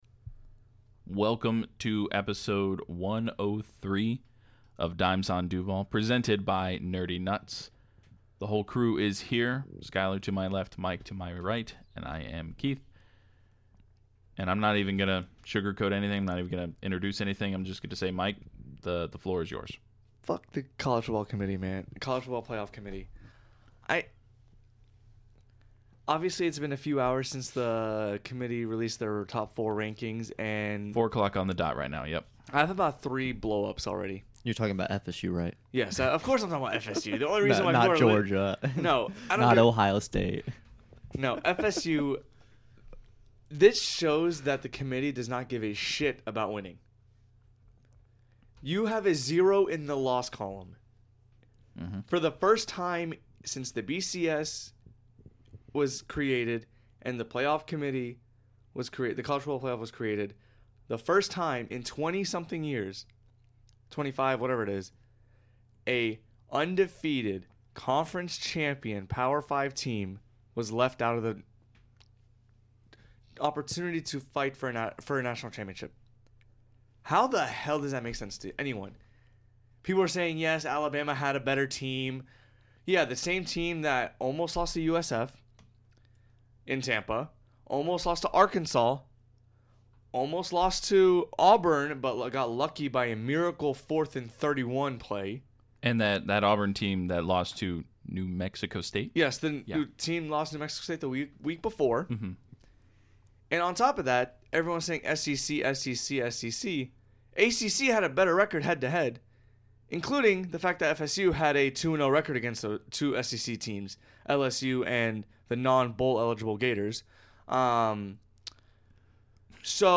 He lets us knowhow pissed he is for the first ~16 minutes of this episode. Honestly, most of this episode is griping about the piss-poor CFP committee. We do end talking about the Jags/Bengals game!